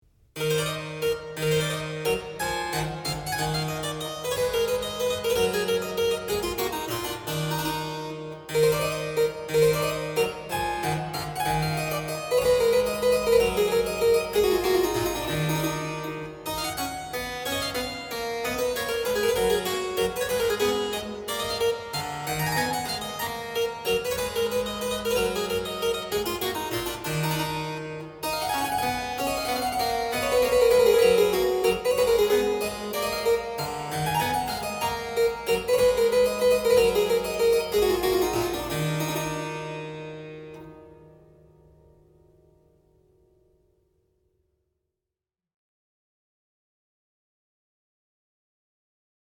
mp3Seixas, Carlos de, Sonata No. 37 in E minor, mvt.
Minuet